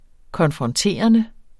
Udtale [ kʌnfʁʌnˈteˀʌnə ]